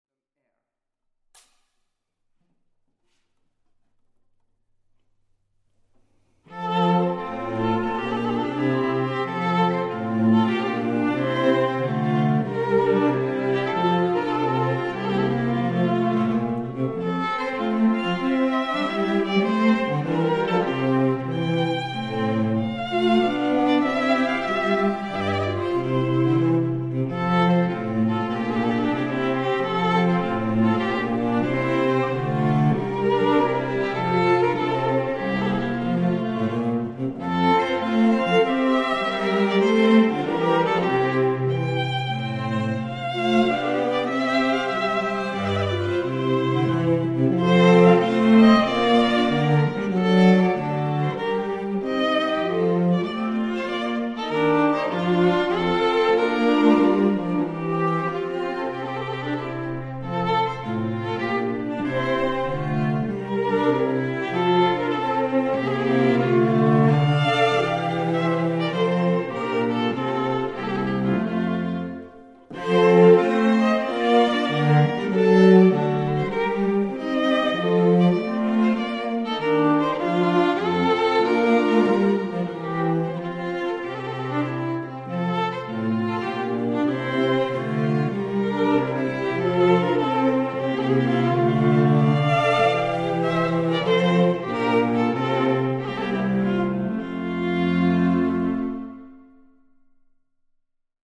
Portobello Strings | Contemporary and Classical Wedding String Quartet